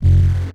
sfx_object_hold_TEST.wav